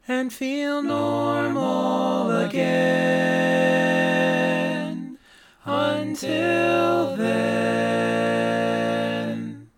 Key written in: B♭ Major
How many parts: 4
Type: Barbershop
All Parts mix:
Learning tracks sung by